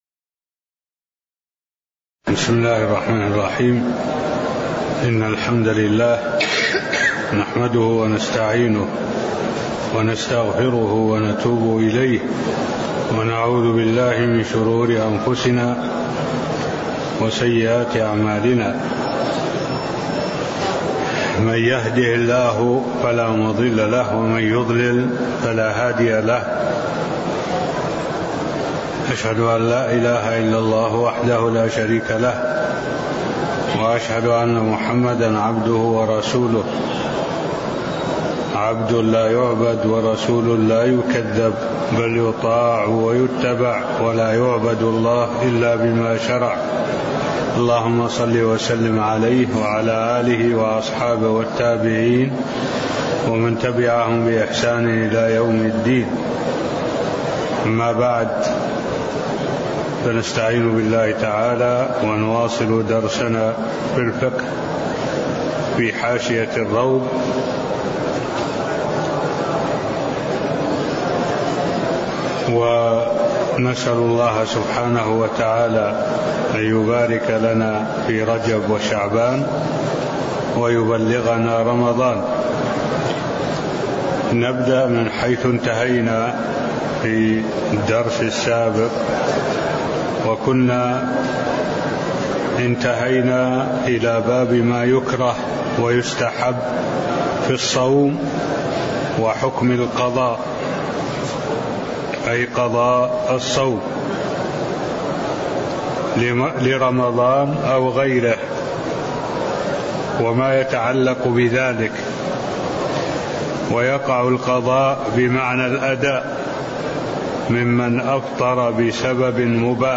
المكان: المسجد النبوي الشيخ: معالي الشيخ الدكتور صالح بن عبد الله العبود معالي الشيخ الدكتور صالح بن عبد الله العبود باب ما يكره ويستحب في الصوم (07)